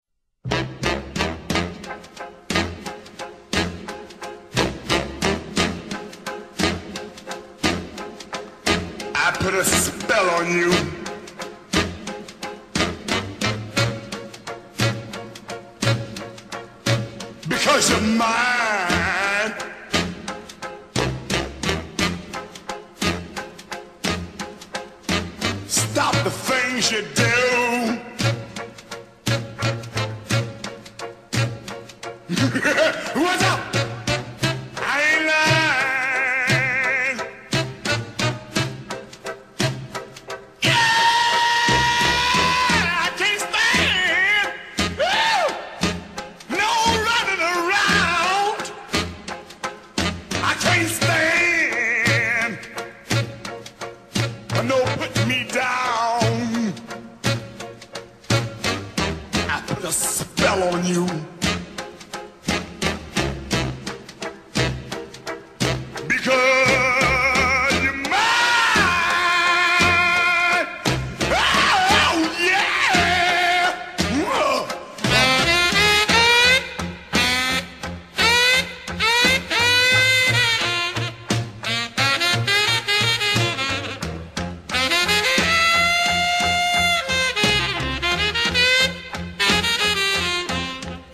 blues